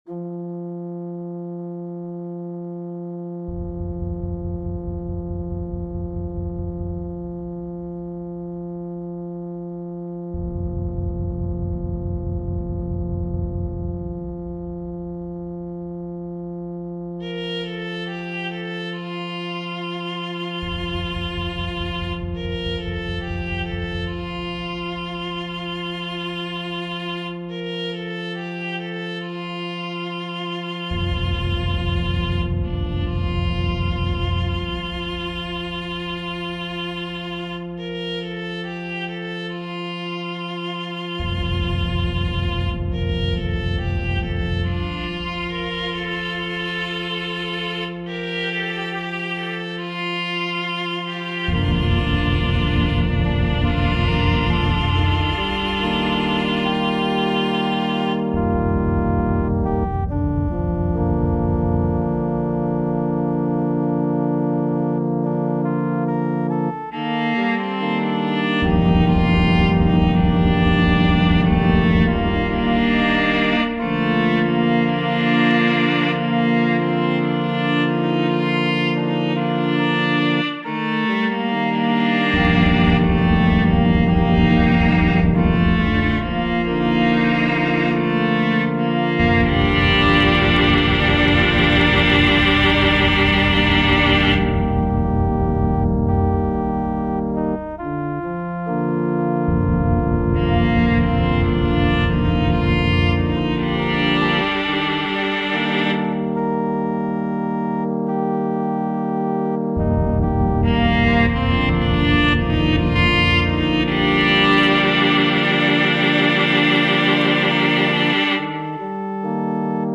for 2 violins, bagpipes, piano, organ and drums